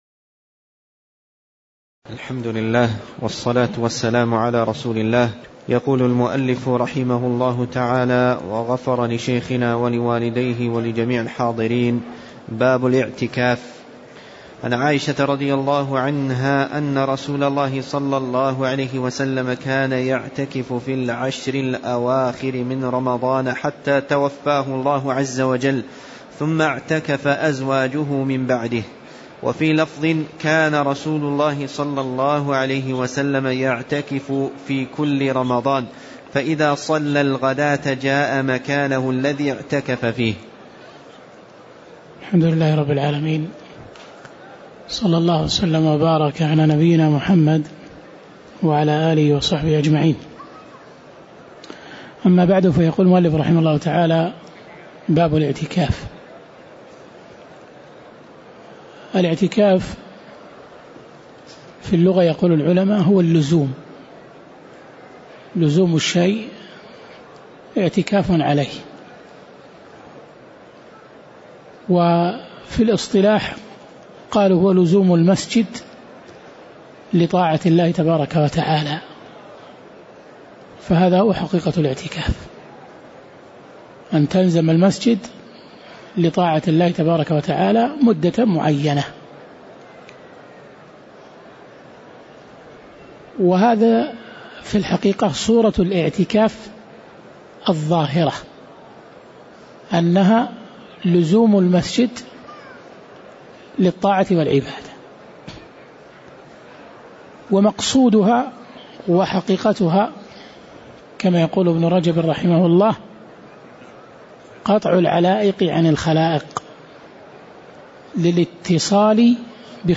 تاريخ النشر ٥ شعبان ١٤٣٧ هـ المكان: المسجد النبوي الشيخ